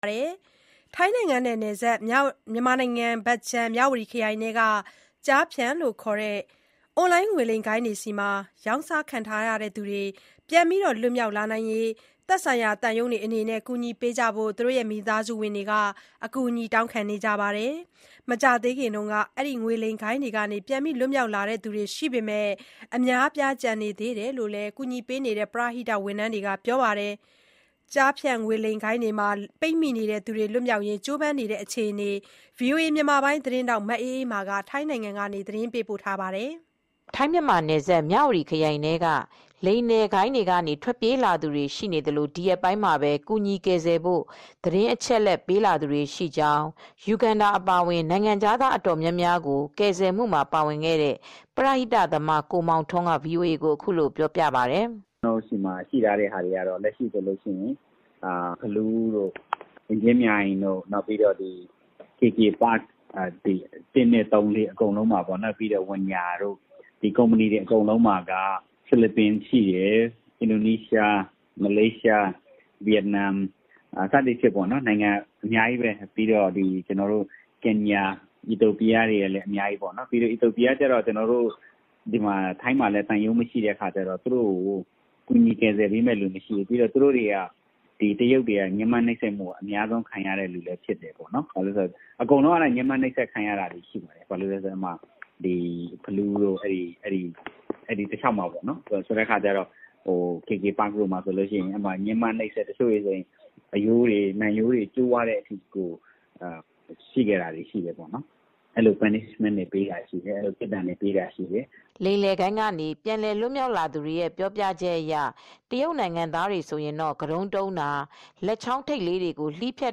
ထိုင်းနိုင်ငံကနေ သတင်းပေးပို့ထားပါတယ်။